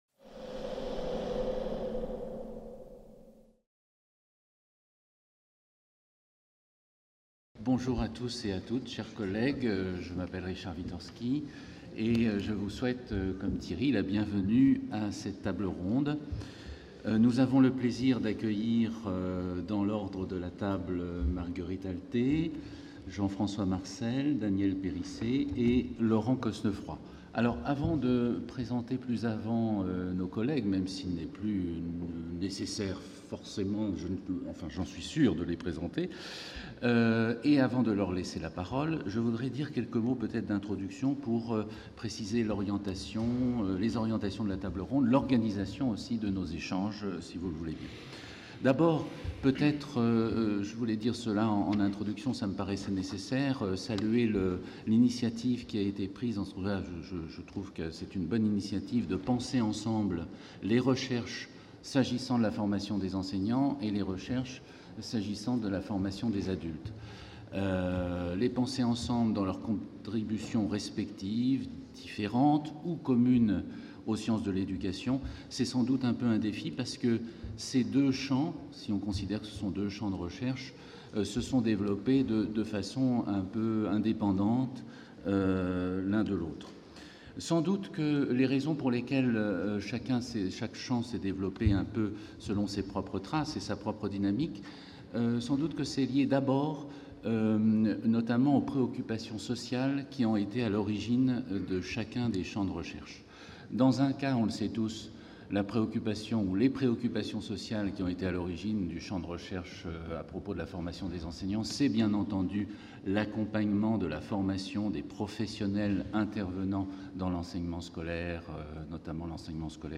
50 ans de sciences de l'éducation - 04 Table ronde : Formation des enseignants, formation des adultes | Canal U
(Amphithéâtre Pierre Daure)